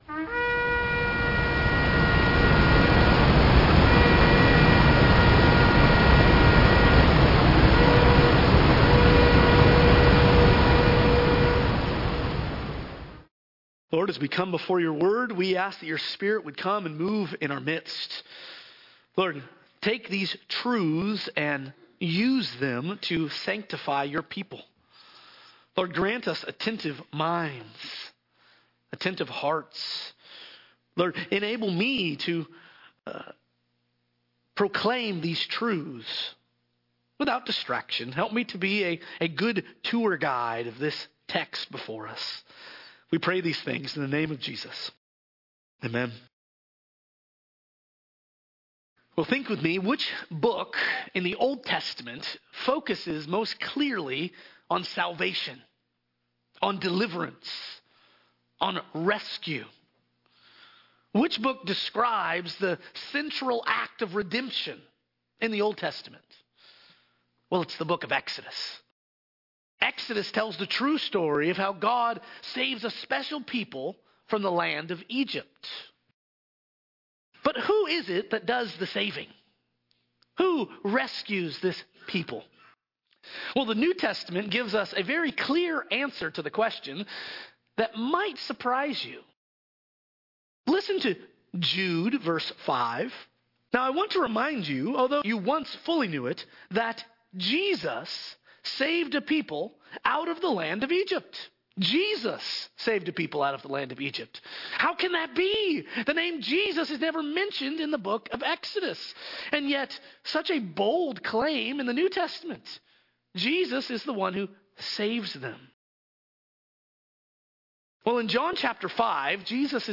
The book of Exodus tells the exciting true story of how the LORD saved His people from slavery in Egypt, then gave them His law and dwelt with them in the tabernacle. In this overview sermon of the entire book of Exodus